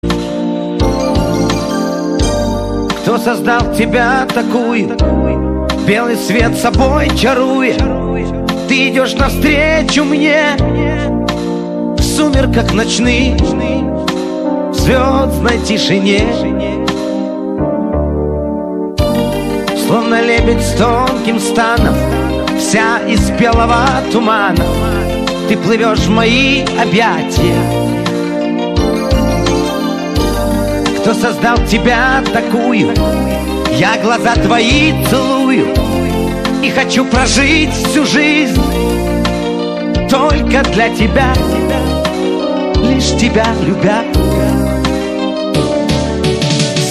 лирика
медленные
Лирическая композиция